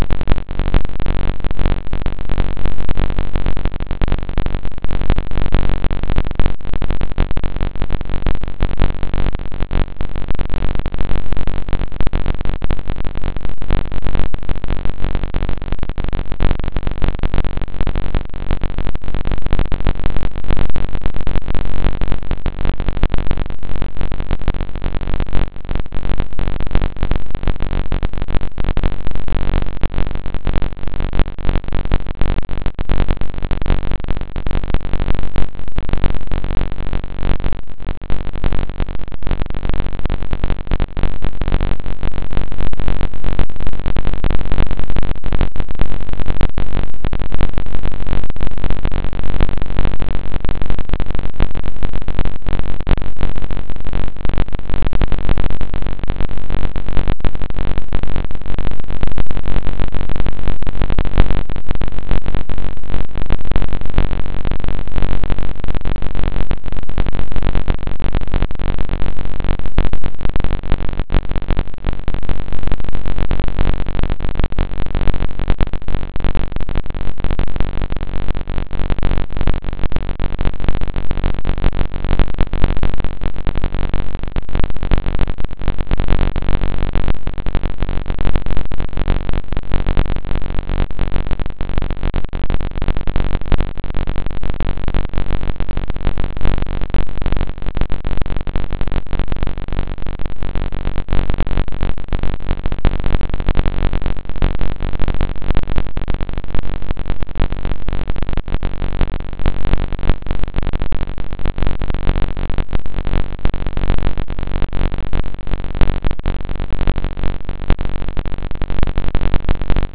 Live Continuum Live Spectrum continuum plot pulsar sound